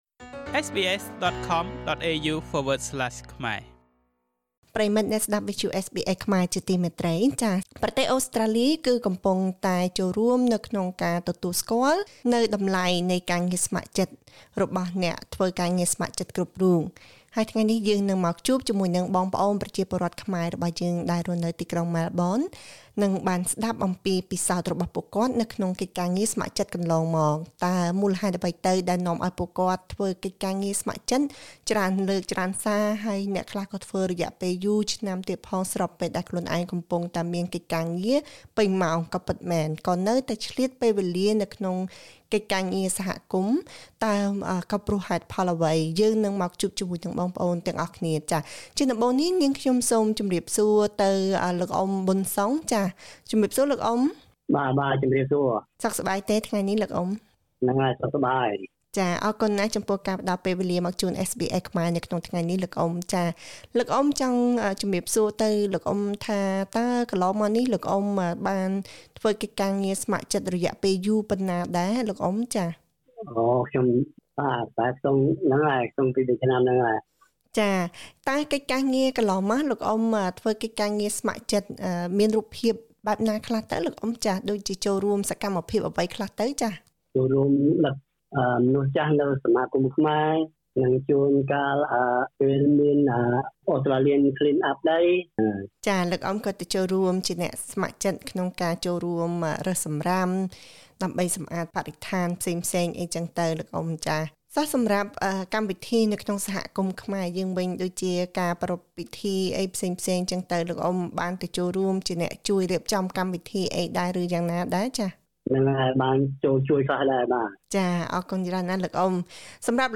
សូមស្តាប់បទសម្ភាសន៍ជាមួយអ្នកស្ម័គ្រចិត្តក្នុងសហគមន៍ខ្មែរ ដែលនឹងចែករំលែកបទពិសោធន៍របស់ពួកគេក្នុងការចូលរួមការងារស្ម័គ្រចិត្តកន្លងមក។